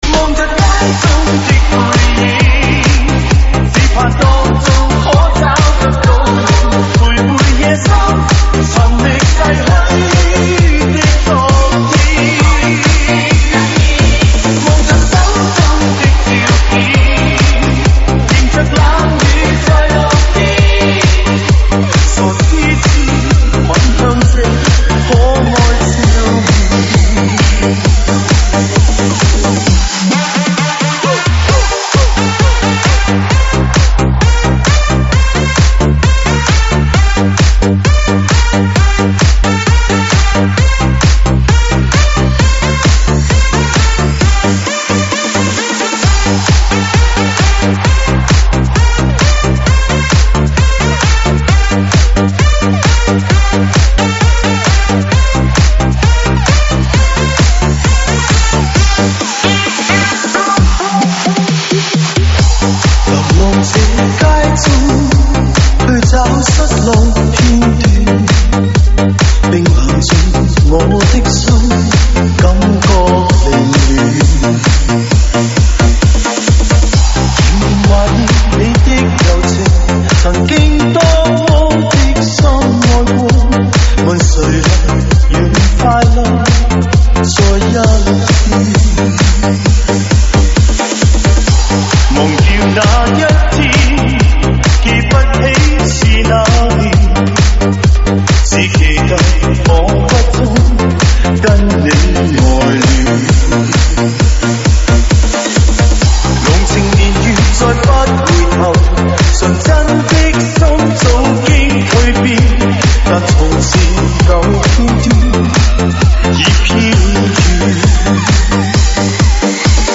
粤语经典